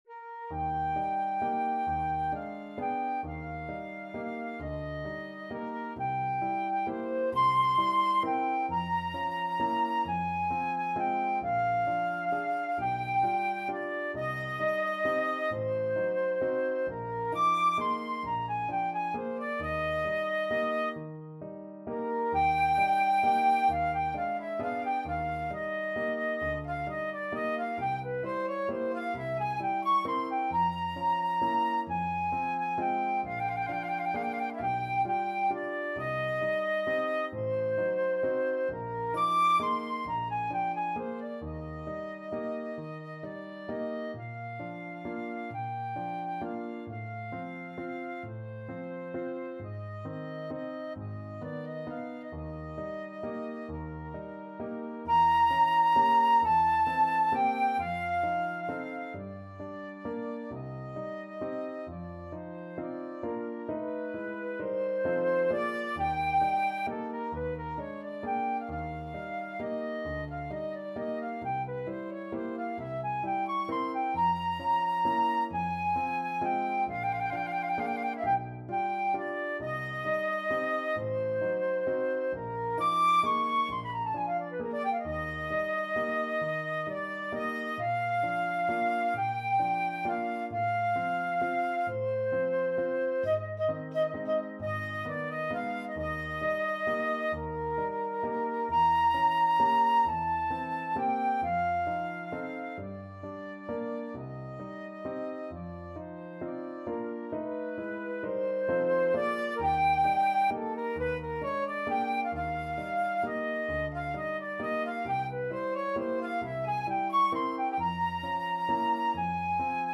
Classical Chopin, Frédéric Nocturne Op.9 No.2 Flute version
FlutePiano
Andante . = 44
12/8 (View more 12/8 Music)
Eb major (Sounding Pitch) (View more Eb major Music for Flute )
Instrument:
Flute  (View more Advanced Flute Music)
Classical (View more Classical Flute Music)